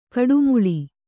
♪ kaḍumuḷi